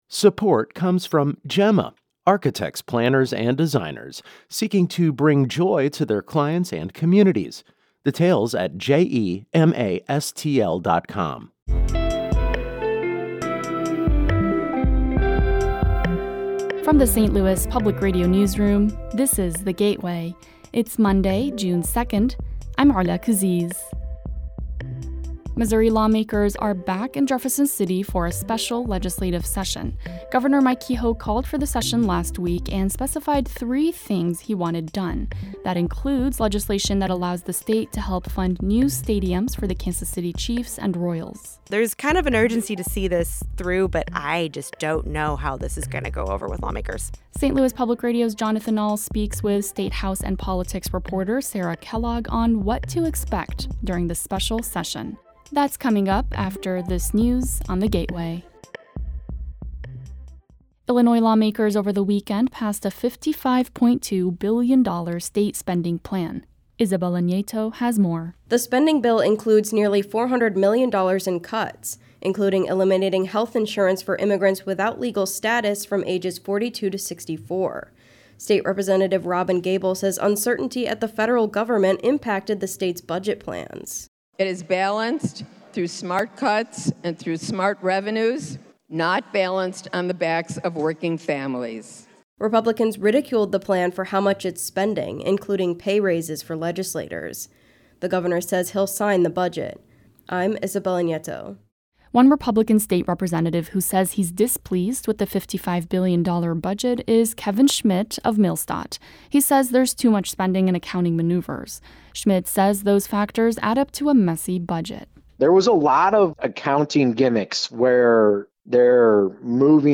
Essential news for the St. Louis region. Every weekday, in about 8 to 10 minutes, you can learn about the top stories of the day, while also hearing longer stories that bring context and humanity to the issues and ideas that affect life in the region.